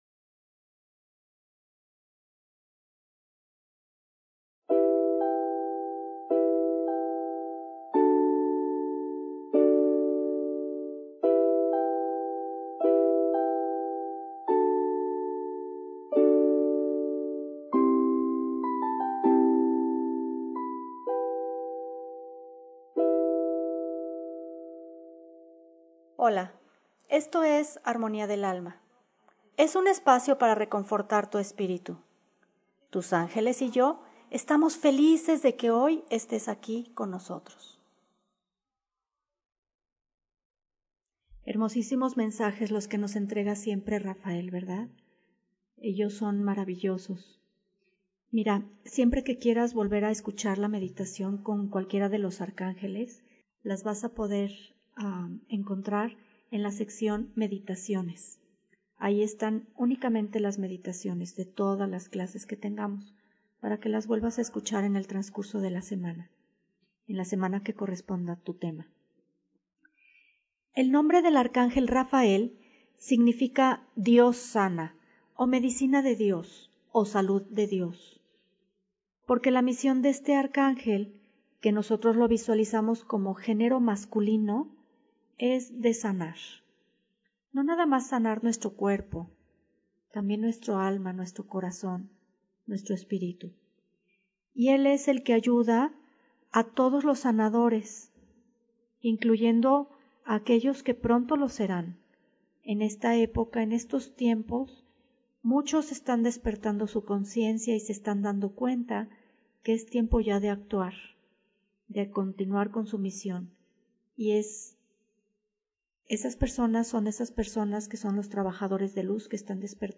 Clase 9: